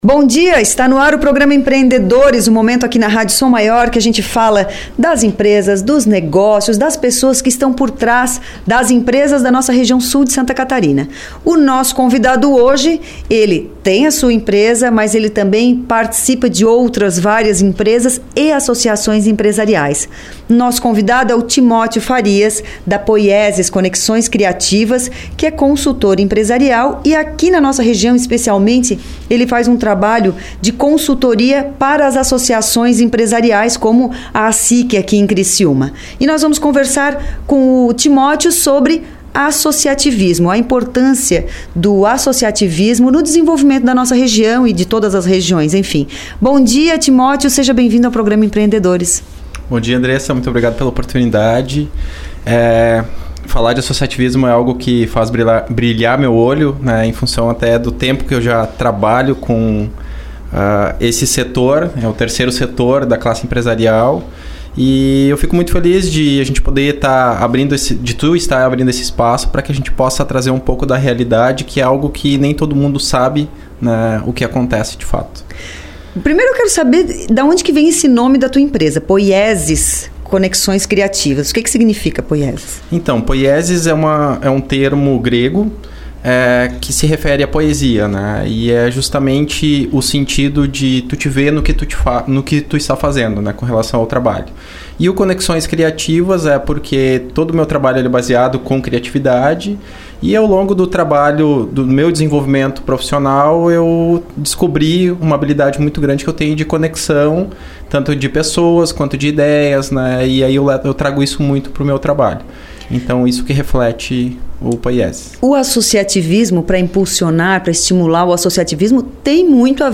Entrevista
O Programa Empreendedores é veiculado originalmente na Rádio Som Maior.